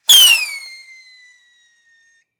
rocketfly.ogg